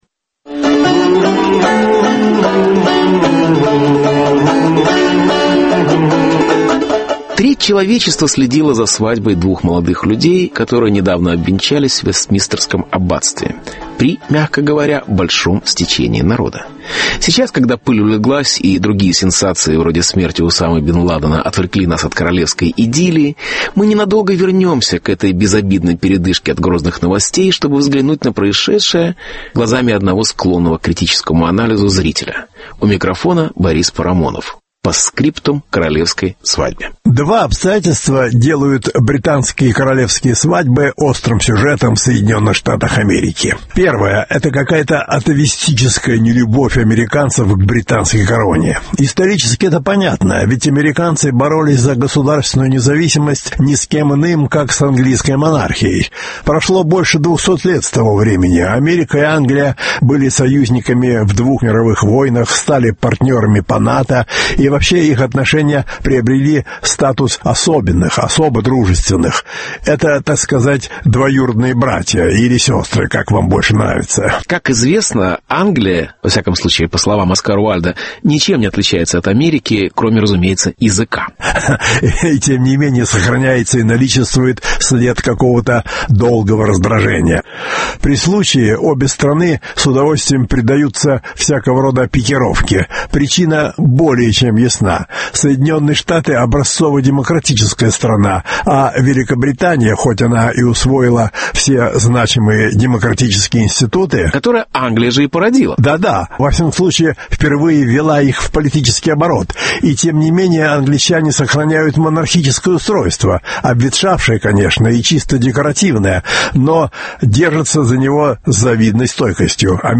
Постскриптум к королевской свадьбе. Беседа с Борисом Парамоновым.